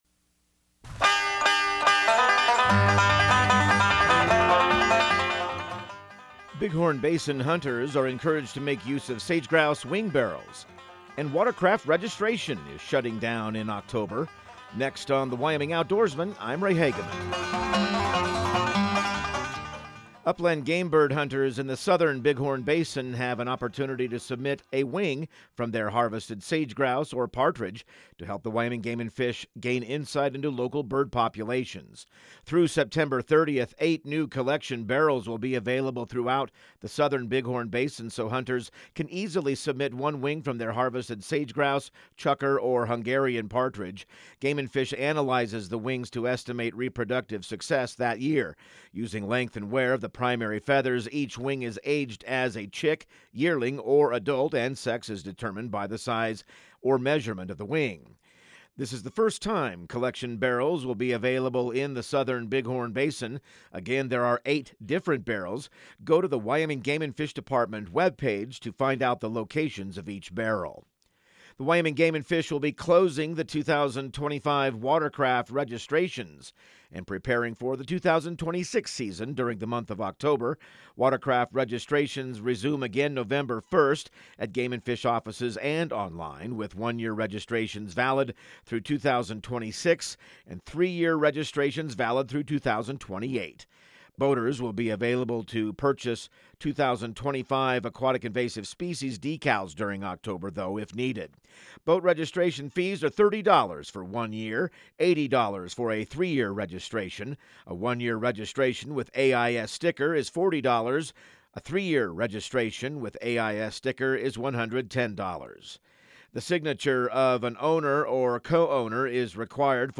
Radio News | Week of September 22